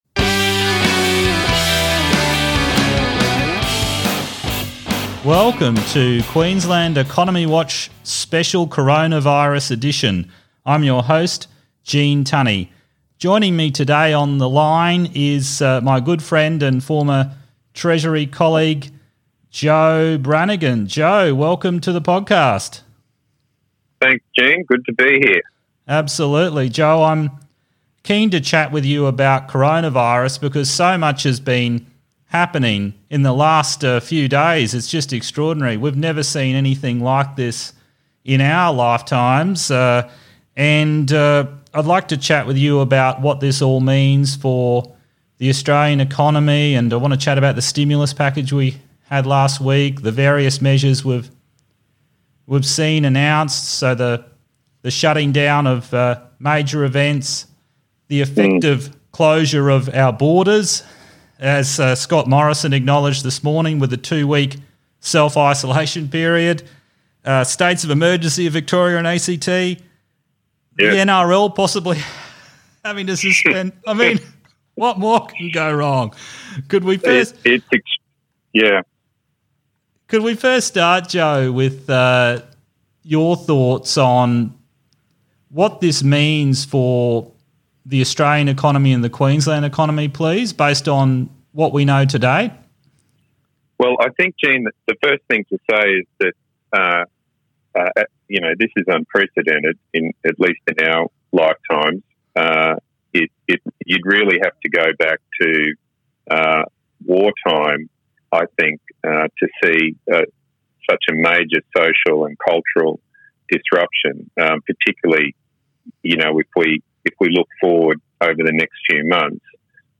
COVID-19 conversation